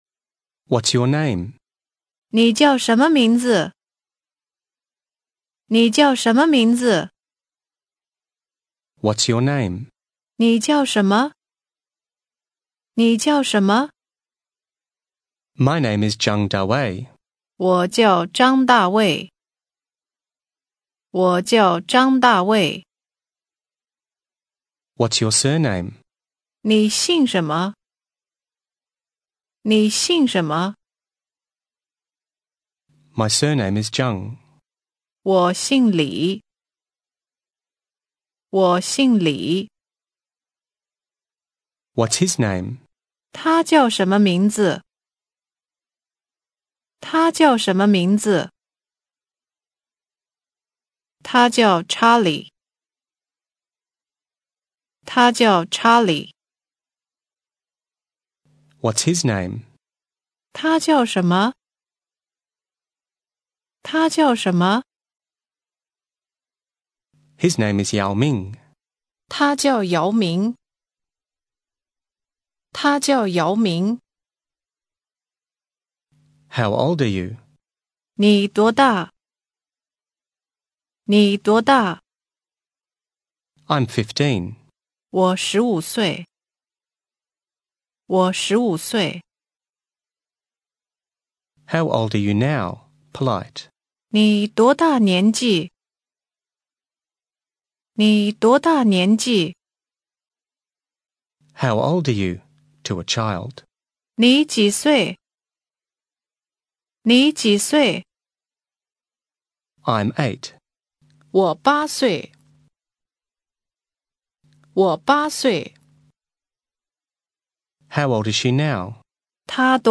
Listen and repeat - Name and age